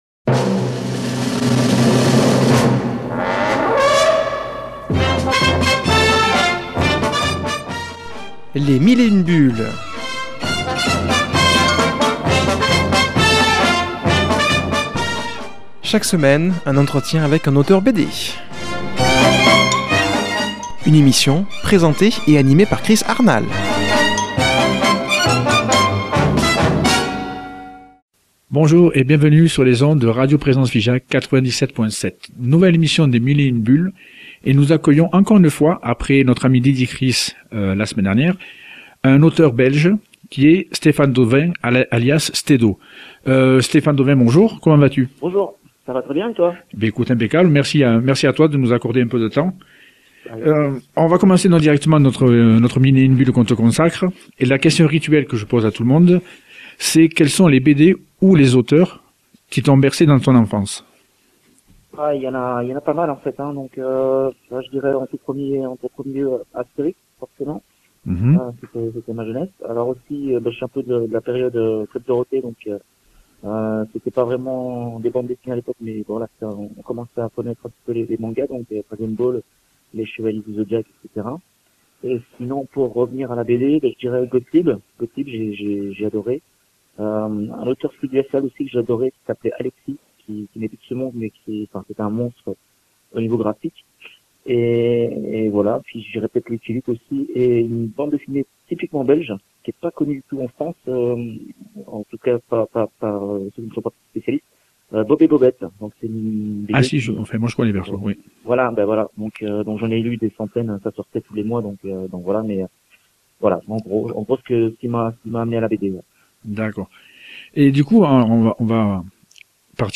qui a comme invitée au téléphone